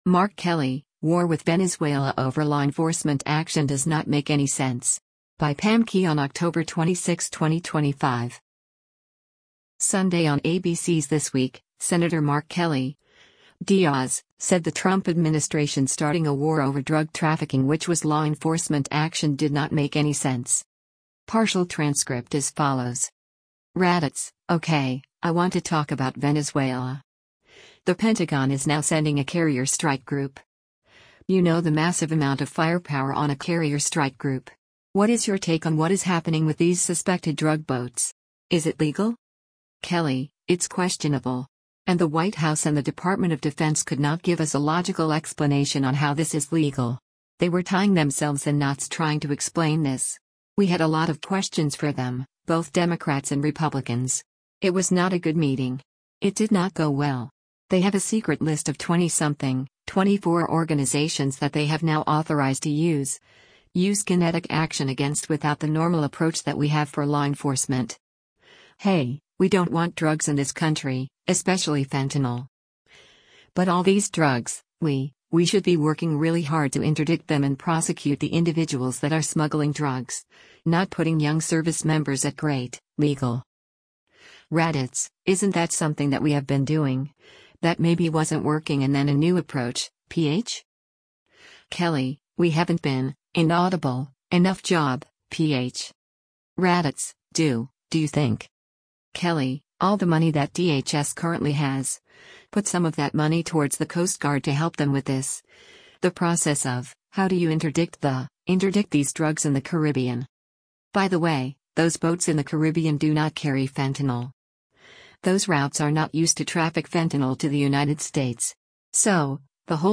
Sunday on ABC’s “This Week,” Sen. Mark Kelly (D-AZ) said the Trump administration starting a war over drug trafficking which was “law enforcement action” did not make any sense.